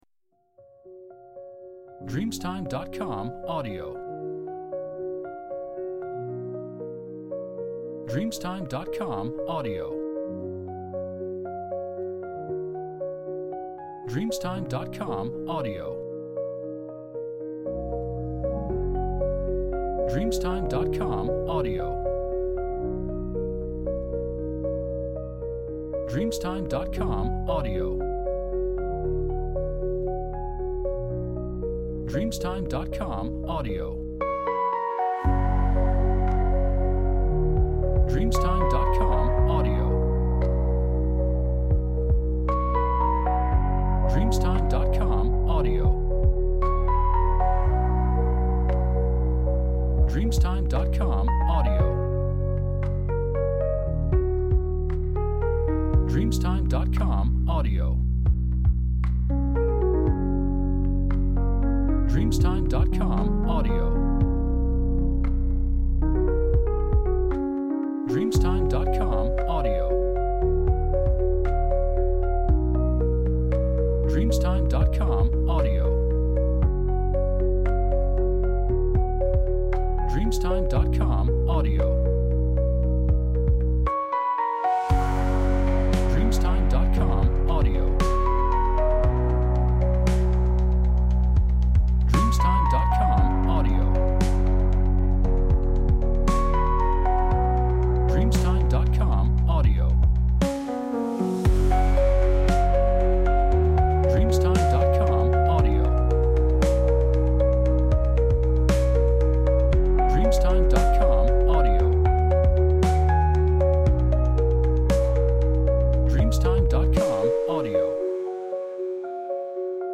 Melodías Alegres Piano